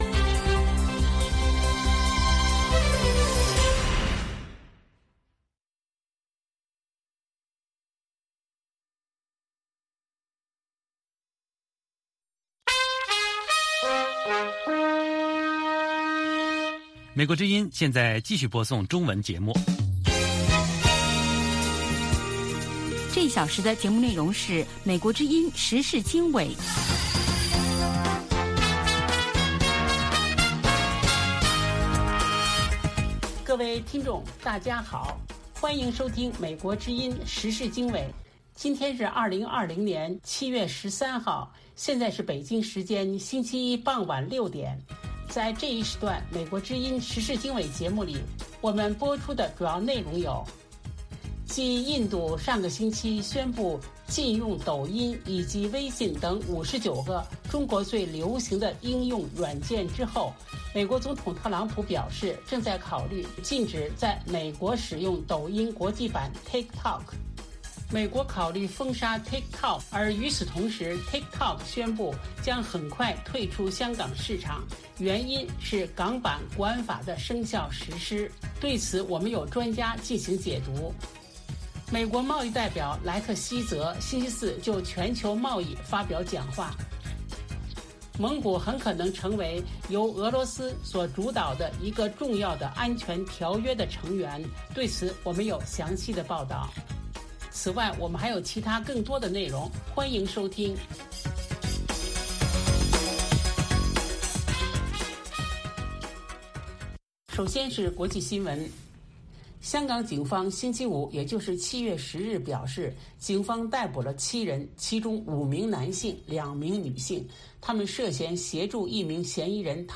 《时事经纬》重点报道美国、世界和中国、香港、台湾的新闻大事，内容包括美国之音驻世界各地记者的报道，其中有中文部记者和特约记者的采访报道，背景报道、世界报章杂志文章介绍以及新闻评论等等。